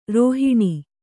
♪ rōhiṇi